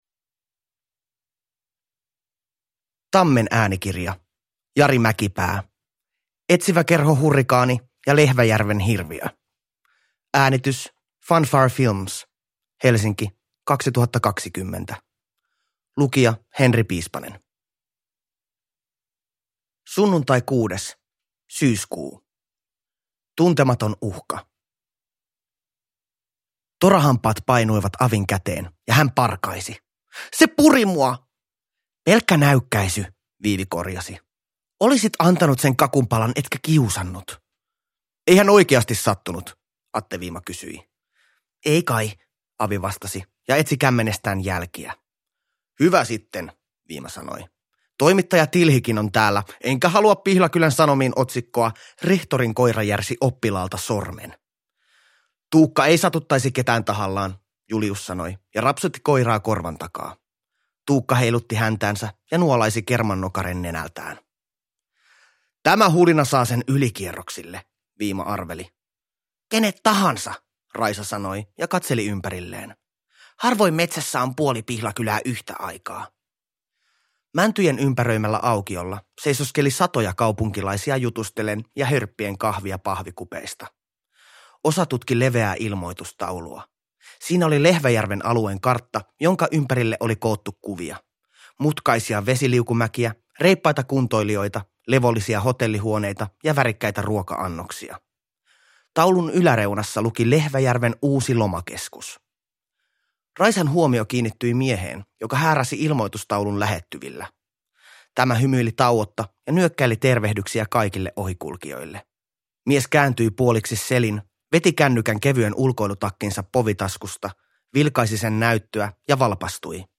Etsiväkerho Hurrikaani ja Lehväjärven hirviö – Ljudbok – Laddas ner